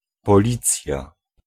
Ääntäminen
Ääntäminen France: IPA: [pɔ.lis] Haettu sana löytyi näillä lähdekielillä: ranska Käännös Ääninäyte Substantiivit 1. policja {f} Muut/tuntemattomat 2. polisa {f} Suku: f .